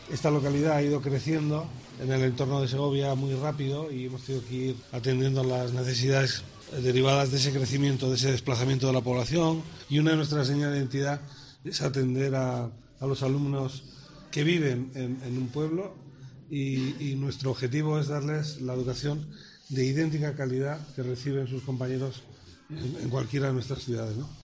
El consejero de Educación, Fernando Rey, en su visita ha remarcado la importancia de que los niños tengan una educación de calidad en las zonas rurales y la importancia de saber adaptarse al crecimiento de estos municipios.
(ESCUCHAR AUDIO CORTE FERNANDO REY 'LAS CAÑADAS')